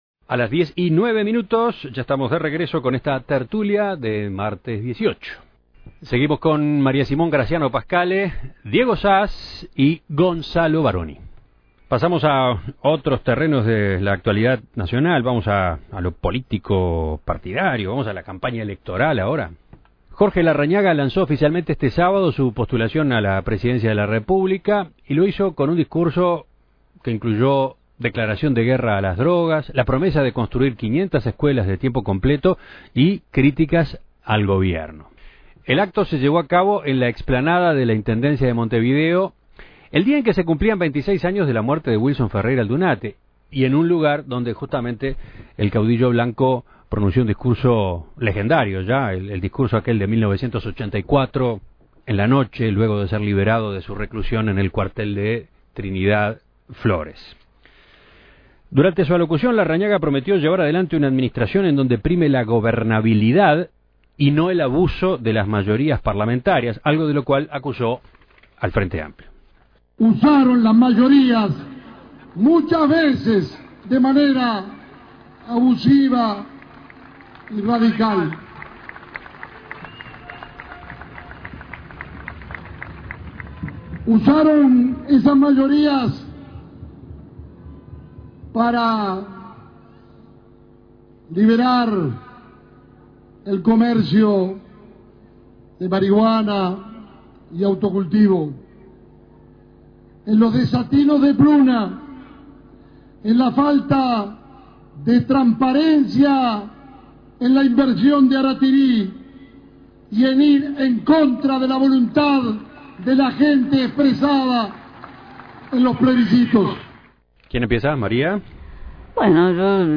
El acto se llevó a cabo en la explanada de la Intendencia de Montevideo, el día en que se cumplían 26 años de la muerte de Wilson Ferreira Aldunate y en el mismo lugar donde el caudillo blanco pronunció un discurso histórico, en 1984, luego de ser liberado de su reclusión en el Cuartel de Trinidad, Flores.